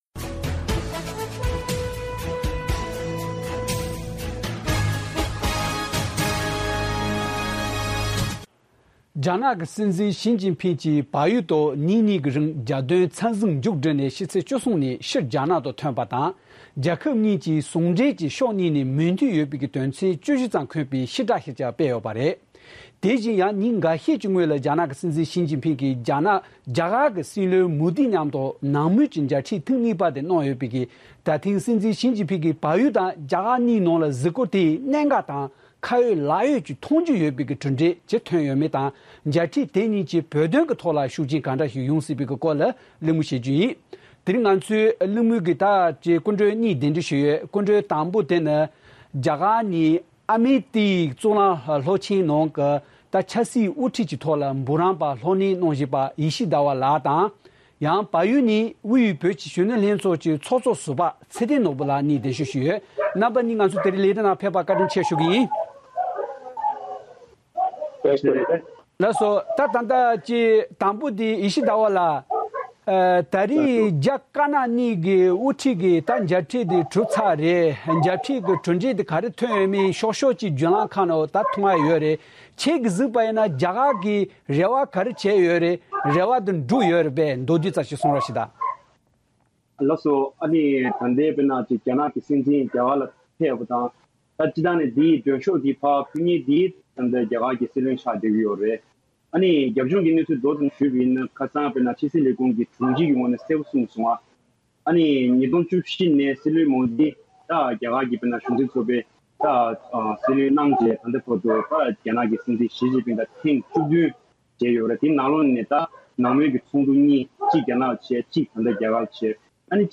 ༄༅༎རྒྱ་ནག་གི་སྲིད་འཛིན་ཞི་ཅིན་ཕིང་བལ་ཡུལ་དུ་རྒྱལ་དོན་འཚམས་གཟིགས་སུ་ཕེབས་སྐབས་བོད་མི་ཚོའི་ཐོག་དམ་བསྒྲགས་ཀྱིས་དཀའ་ངལ་ཇི་ལྟར་བཟོས་སྐོར་དངོས་སུ་ཉམས་མྱོང་བྱུང་བའི་འབྲེལ་ཡོད་མི་སྣ་གཉིས་དང་ཁ་བརྡ་བྱས་པ་ཞིག་དང་།